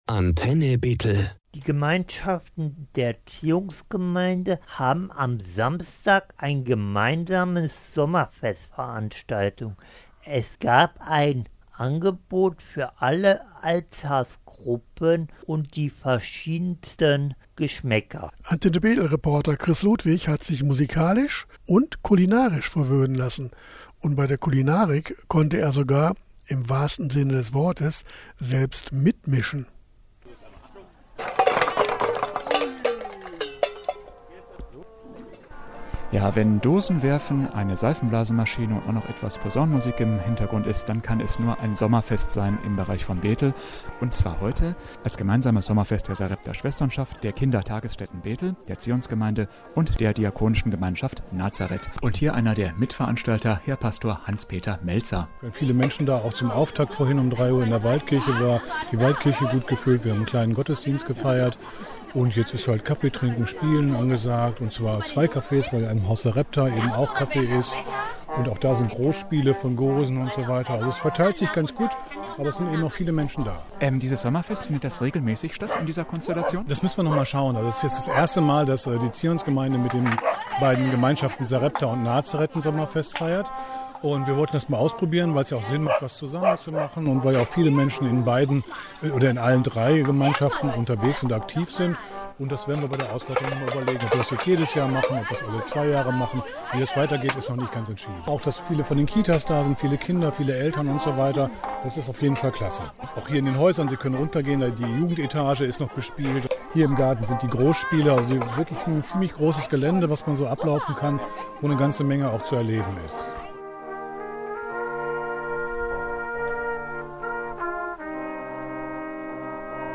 Sommerfest in Zionsgemeinde mit Sarepta-Schwesternschaft und Gemeinschaft Nazareth!
Reportage-Sommerfest-Zionsgemeinde-2025.mp3